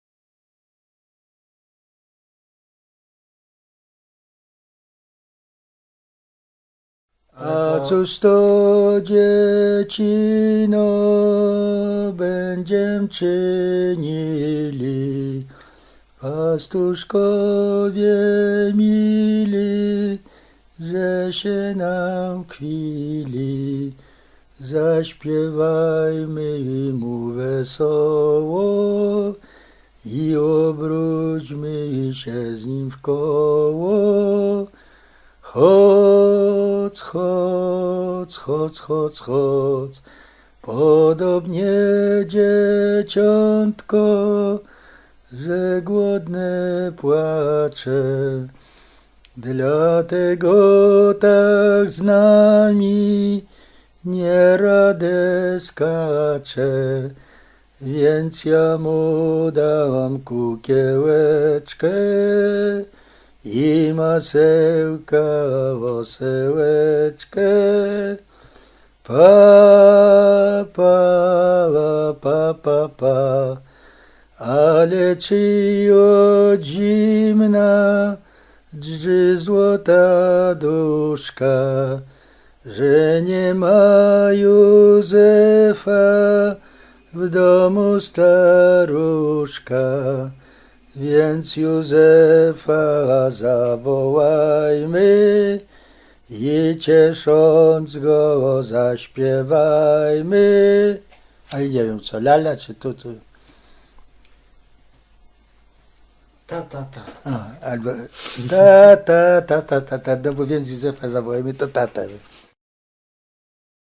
Roztocze
Kolęda